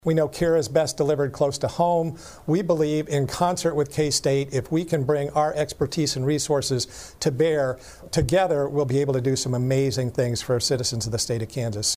The two organizations made the announcement during a joint news conference.